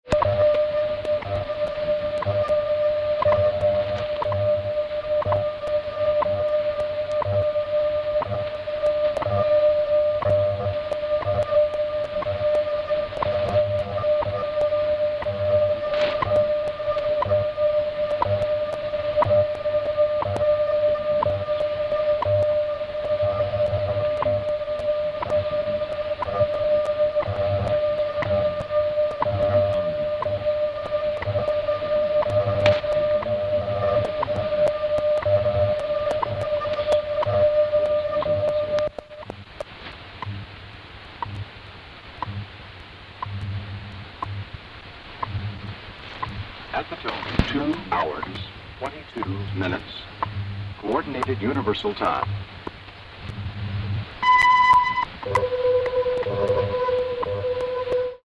Heavy Aurora Flutter: on WWV, 5 MHz. This was recorded off of the Grand Junction Global Tunner. Aurora was visible as far south as Ohio on this date.